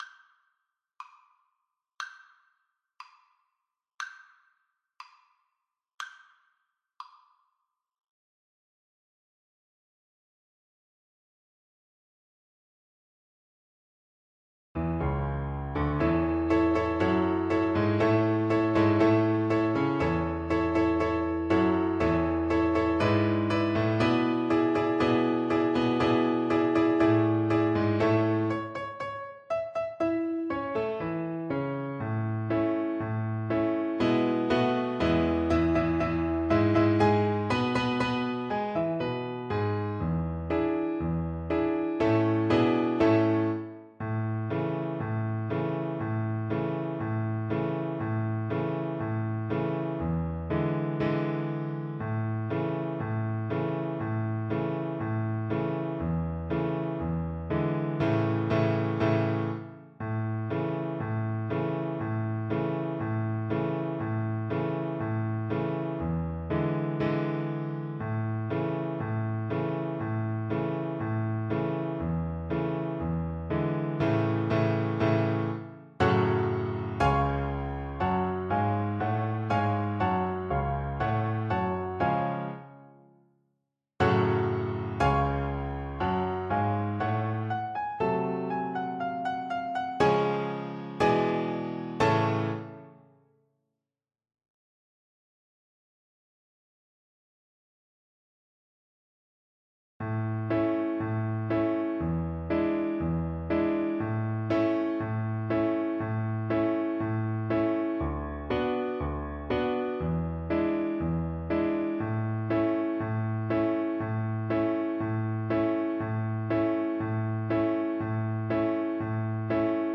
Violin
2/4 (View more 2/4 Music)
Arrangement for Violin and Piano
A major (Sounding Pitch) (View more A major Music for Violin )
Classical (View more Classical Violin Music)